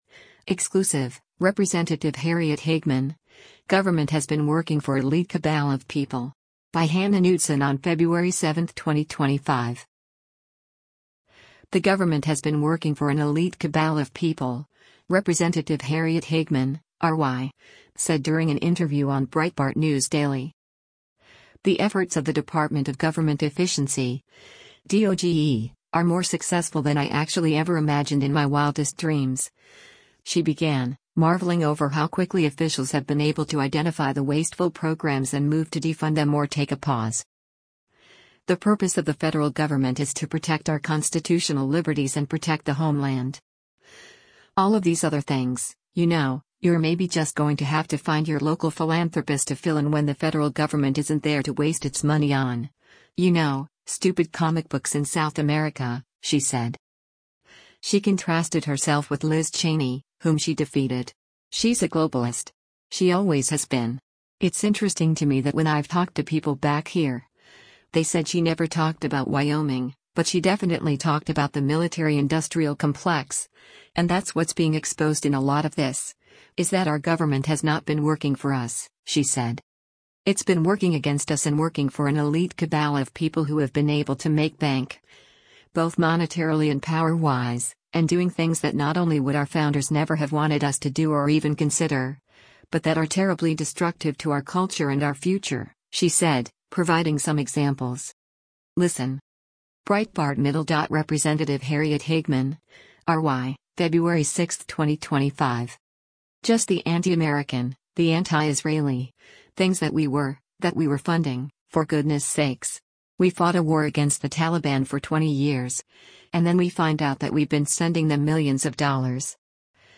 The government has been working for an “elite cabal of people,” Rep. Harriet Hageman (R-WY) said during an interview on Breitbart News Daily.
Breitbart News Daily airs on SiriusXM Patriot 125 from 6:00 a.m. to 9:00 a.m. Eastern.